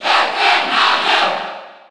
Category:Crowd cheers (SSB4) You cannot overwrite this file.
Dr._Mario_Cheer_French_PAL_SSB4.ogg